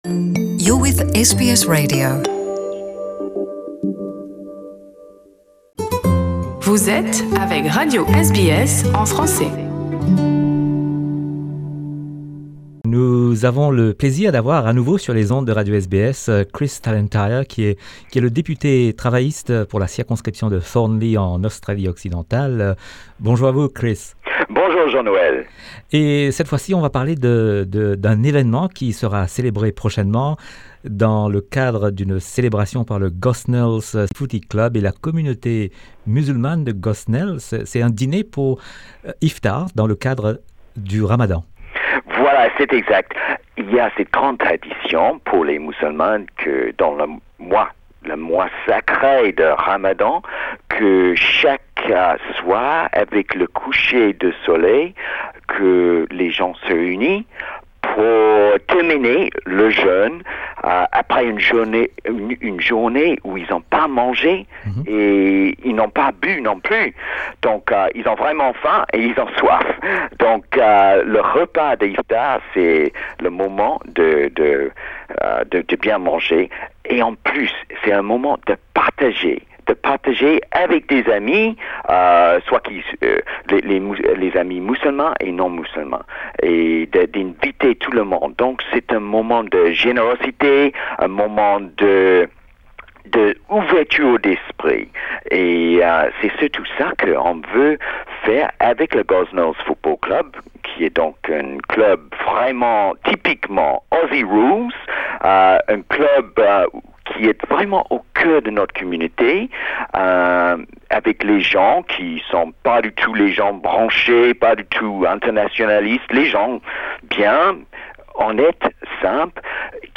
Nous sommes avec Chris Tallentire, le deputé travailliste de la circonscription de Thornlie en Australie Occidentale. Il parle de cette initiative d'organiser le repas de l'Iftar, le repas qui est pris chaque soir par les musulmans au coucher du soleil pendant le jeûne du mois de ramadan. Ce repas aura lieu le 6 juin prochain avec la collaboration du Gosnells Footy Club et la communauté musulmane de Thornlie de WA.